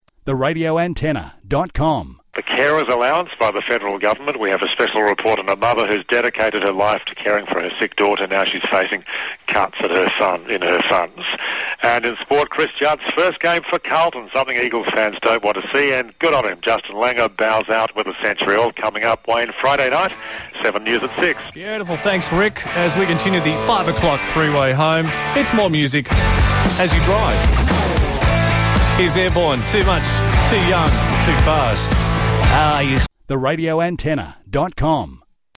RA Aircheck 96FM
Features news update